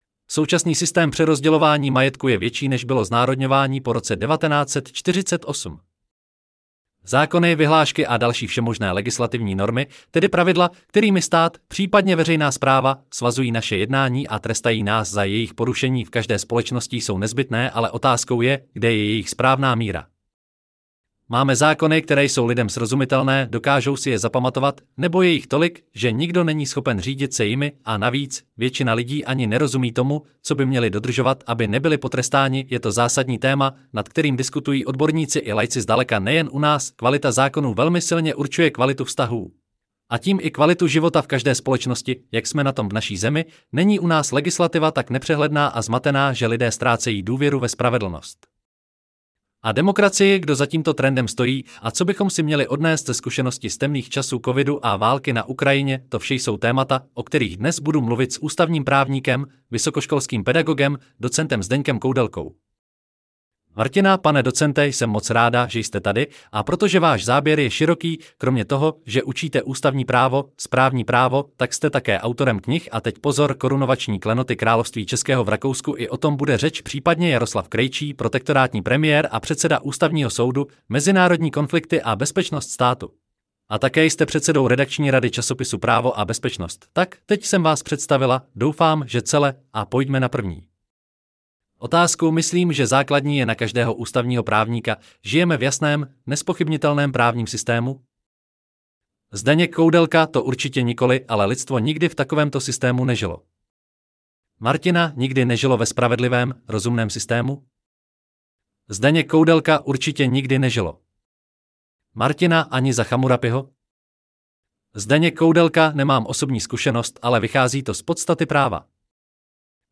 To vše jsou témata, o kterých dnes budu mluvit s ústavním právníkem, vysokoškolským pedagogem, docentem Zdeňkem Koudelkou.